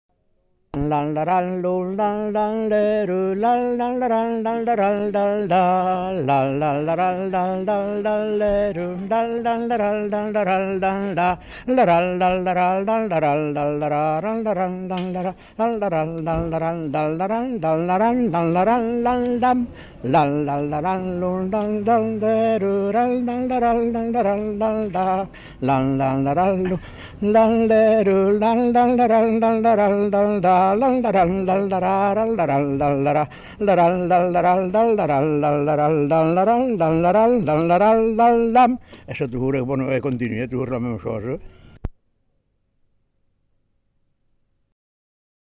Lieu : Sauveterre
Genre : chant
Effectif : 1
Type de voix : voix d'homme
Production du son : fredonné
Danse : républicaine